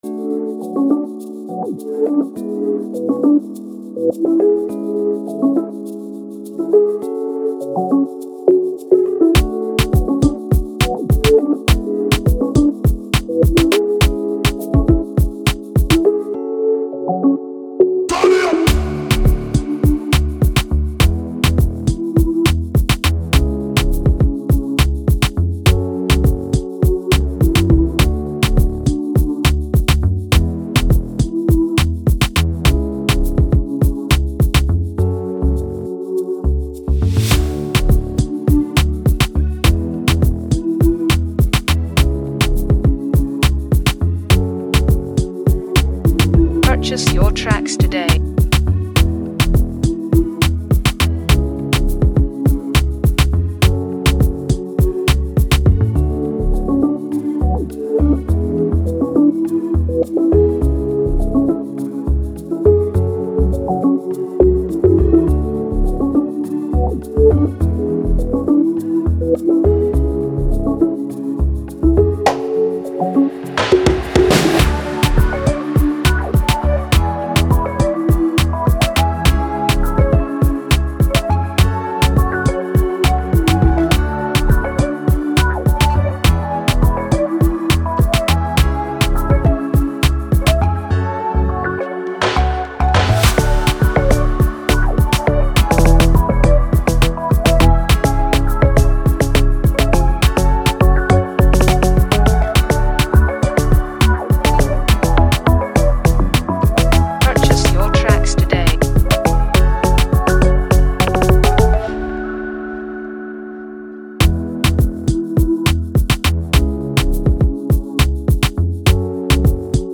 ” echoes the vibrant rhythms of Nigeria